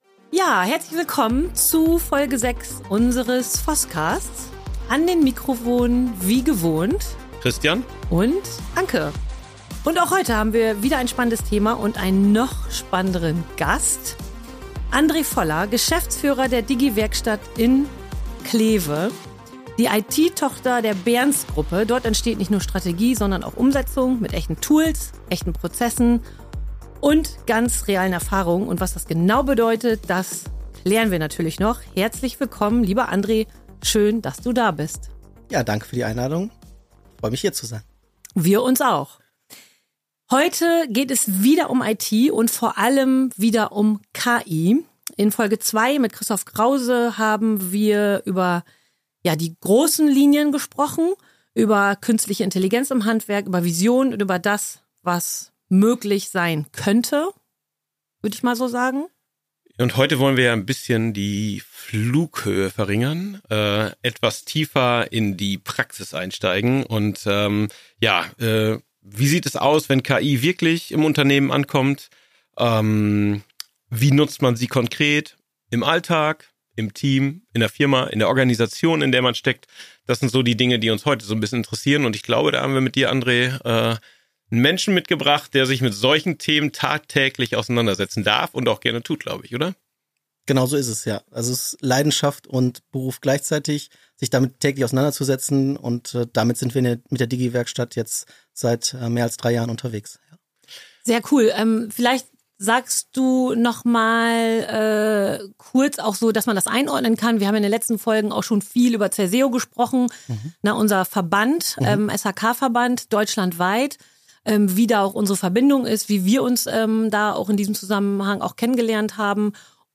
Folge #6 | Digitalisierung und KI, die wirkt | Im Gespräch